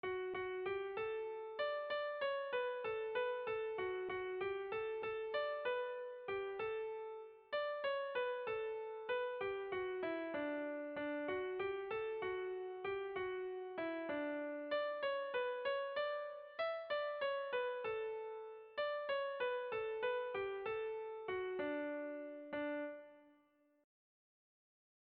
Erromantzea
Neurrian ez dabil zuzen zuzen.
ABD